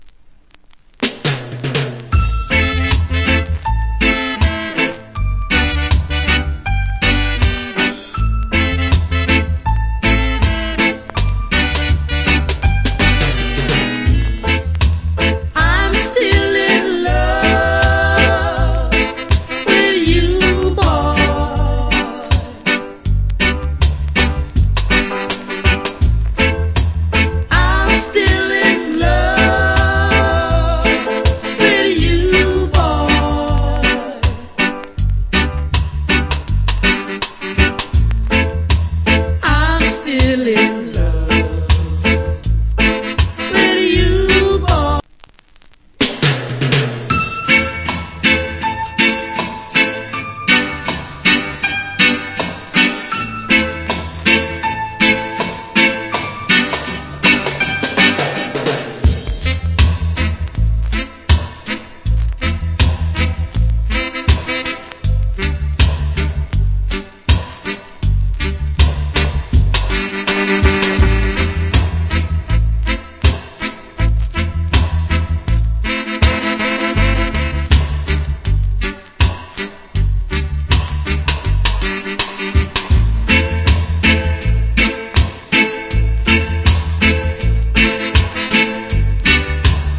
Nice Female Reggae Vocal.